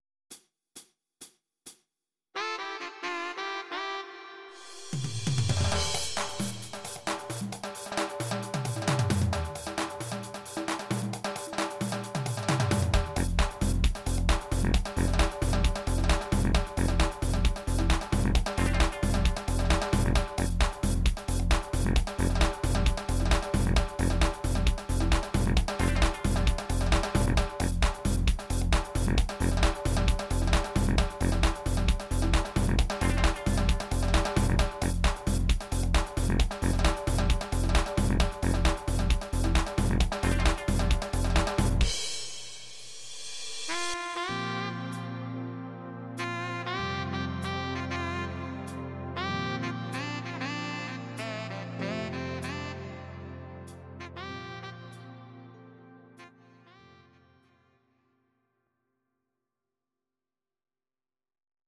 Audio Recordings based on Midi-files
Pop, Instrumental, 2000s